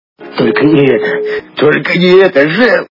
» Звуки » Из фильмов и телепередач » Приключения капитана Врунгеля - Только не это..Только не это шеф